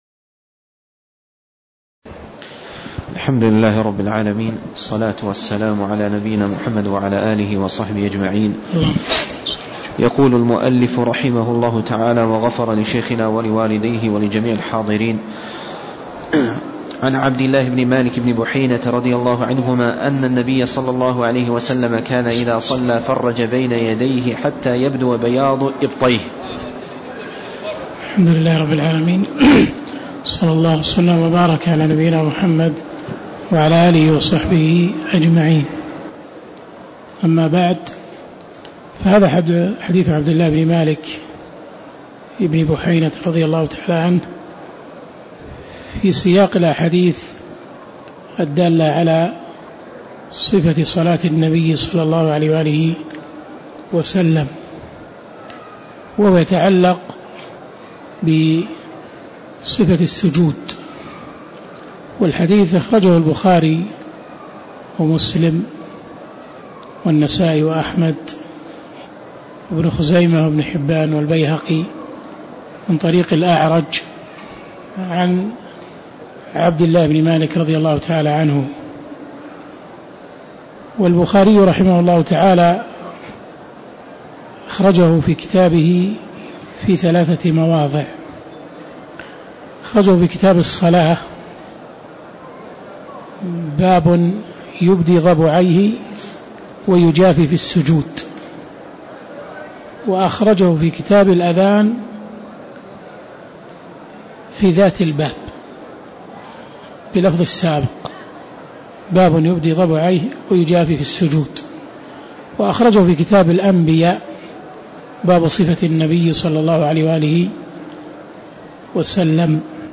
تاريخ النشر ١٤ شعبان ١٤٣٦ هـ المكان: المسجد النبوي الشيخ